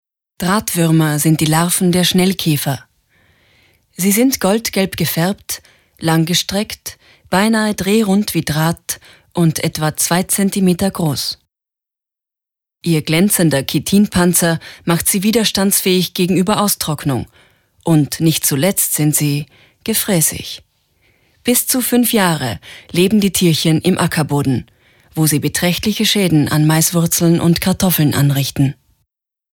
Sprecherin Stimme: natürlich, freundlich, sinnlich Hörbuch & App für Kinder
Sprechprobe: eLearning (Muttersprache):
Drahtwuermer - Off - Text Dokumentation.mp3